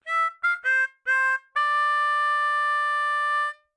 口琴练习剪辑 " 口琴节奏 08 ( 可循环 )
描述：这是一个演奏节奏裂缝的海洋乐队口琴的录音。
Tag: 口琴 节奏 重点 G